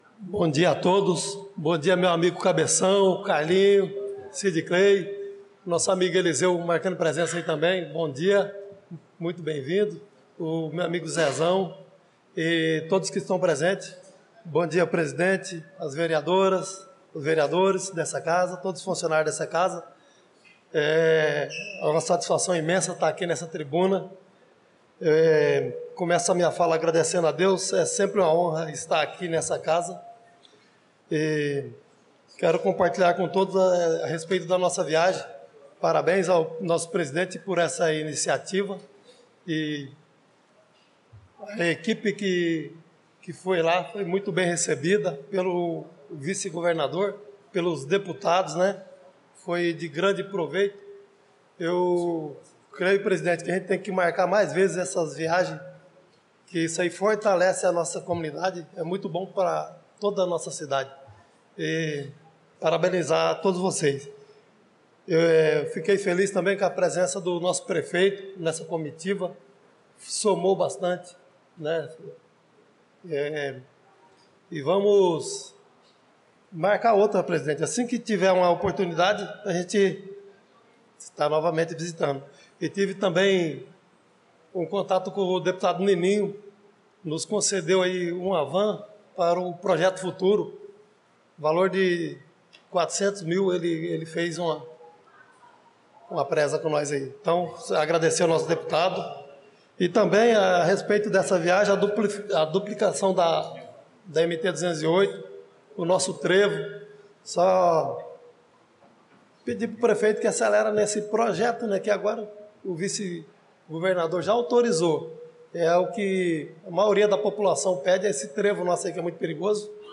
Pronunciamento do vereador Chicão do Motocross na Sessão Ordinária do dia 18/02/2025